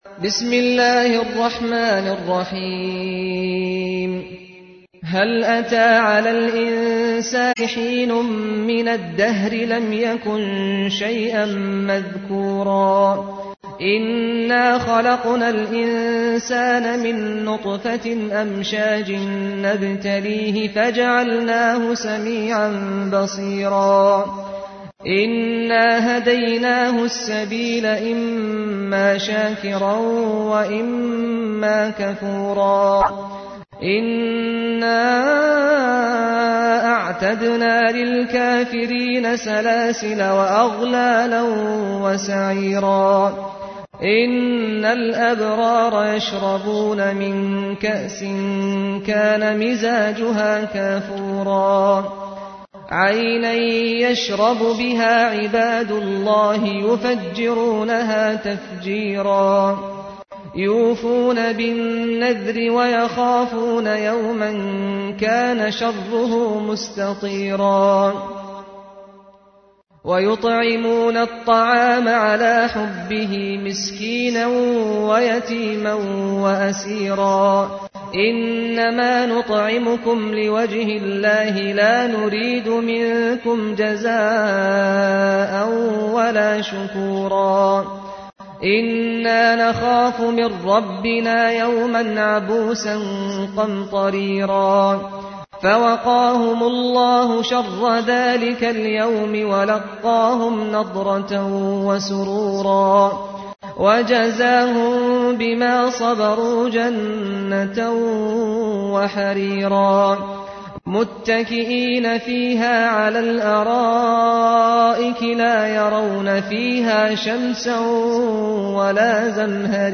ترتیل سوره انسان با صدای استاد سعد الغامدی